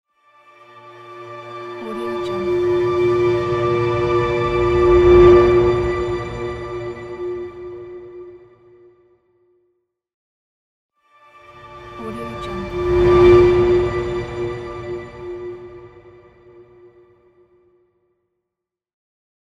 دانلود افکت صوتی Bright Swell
تراک صوتی بدون حق امتیاز Bright Swell یک گزینه عالی برای هر پروژه ای است که به انتقال و حرکت و جنبه های دیگر مانند swell، sweep و pass نیاز دارد.